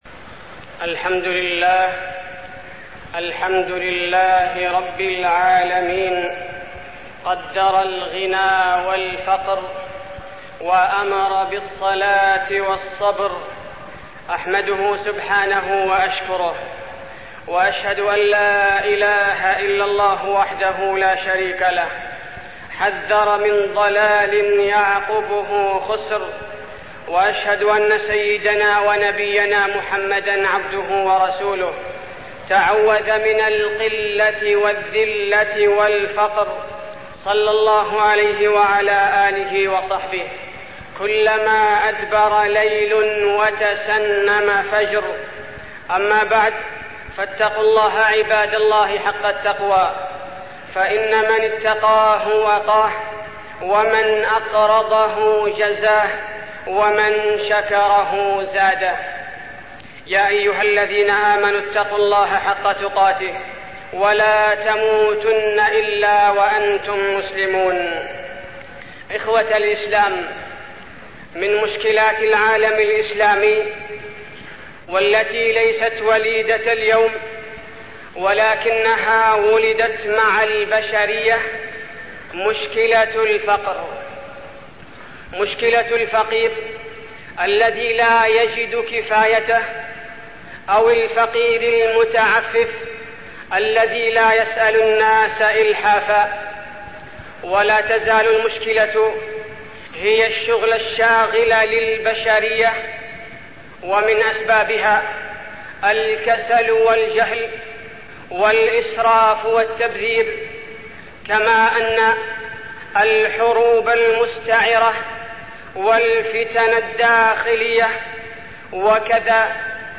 تاريخ النشر ٨ صفر ١٤١٨ هـ المكان: المسجد النبوي الشيخ: فضيلة الشيخ عبدالباري الثبيتي فضيلة الشيخ عبدالباري الثبيتي الفقر وعلاجه